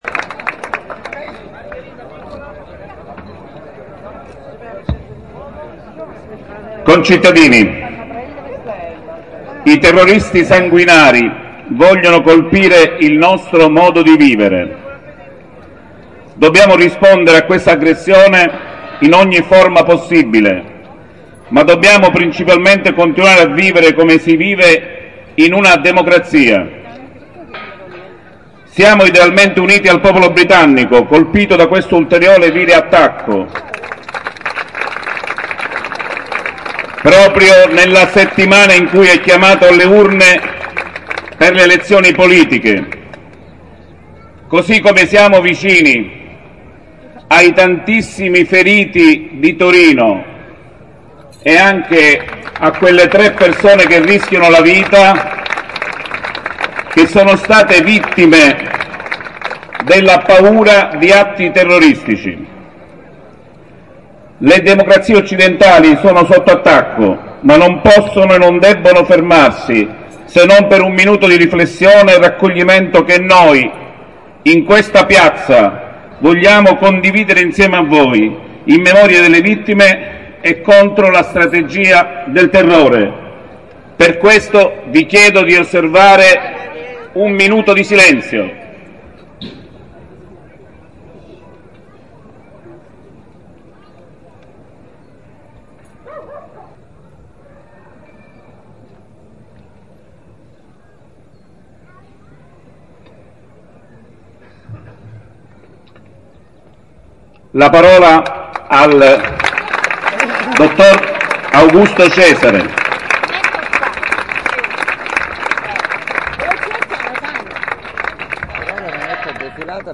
Comizio lista Insieme per Cefalù